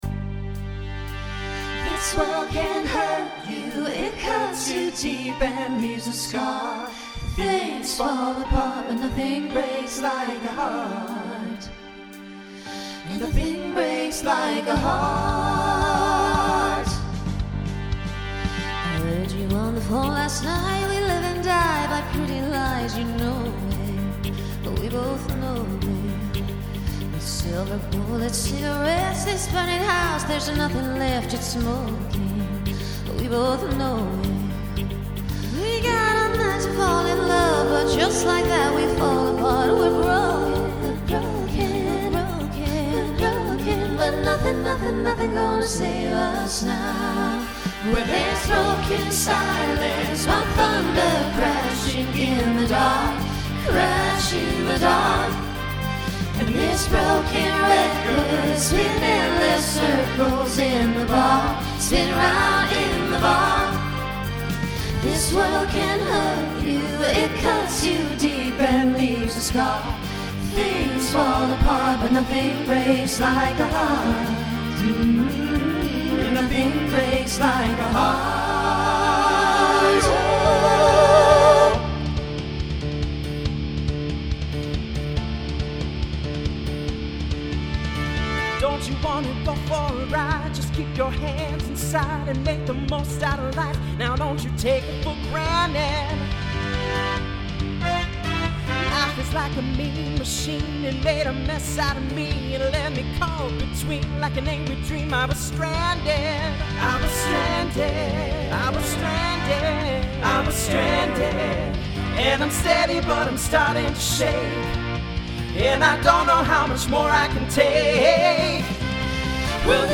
Pop/Dance , Rock Instrumental combo
Mid-tempo
Voicing SATB